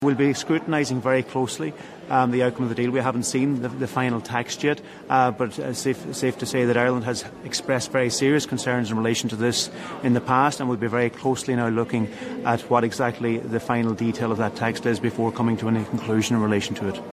Minister Charlie McConalogue, says the proposals will be looked at closely………………